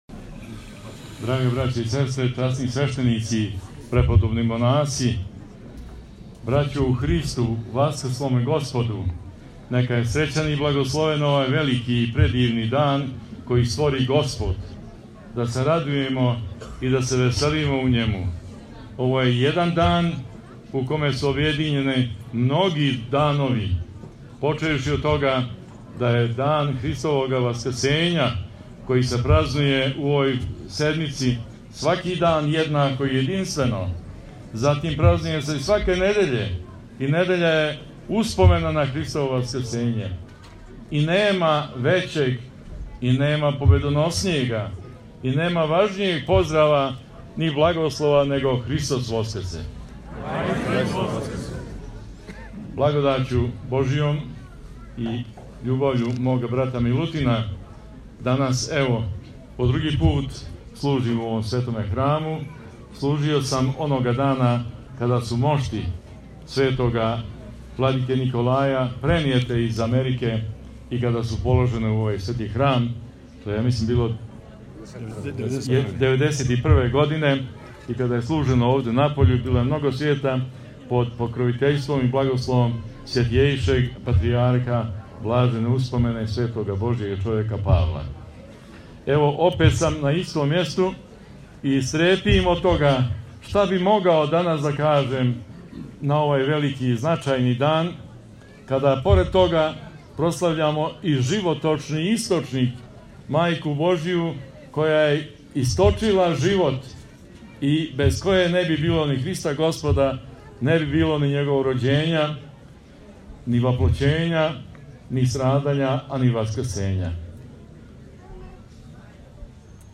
У манастиру Лелић богомспасаване Епархије ваљевске, данас је торжественом Светом архијерејском Литургијом началствовао Преосвећени умировљени Епископ канадски г. Георгије (Ђокић), уз саслужење 24 свештеника и свештеномонаха и 5 свештенођакона из више Епархија СПЦ. Благодарећи колегама из радија “Источник” Епархије ваљевске који данас прослављају славу и 10 година постојања и рада, у прилици смо да чујемо литургијску беседу Владике Георгија.